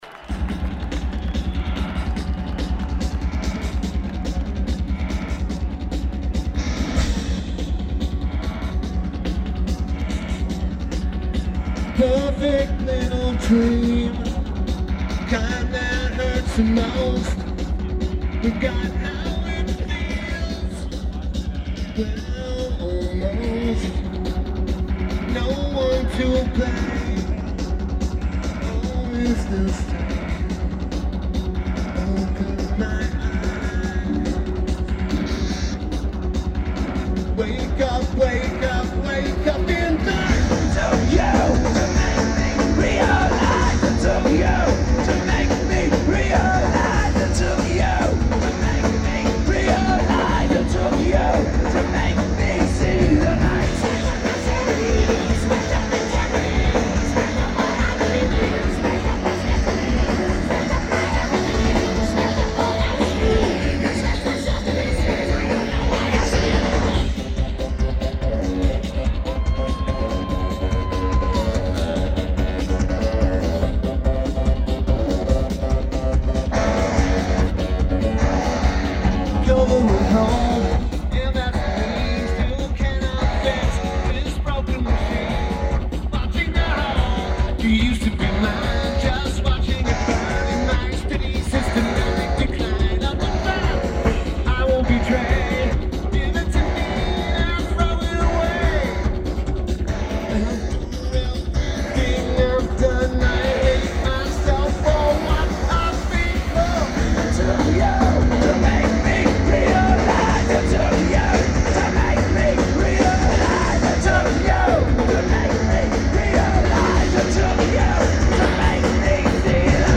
Hayden Homes Amphitheatre
Lineage: Audio - AUD (AT853 (4.7k mod) + Sony PCM-A10)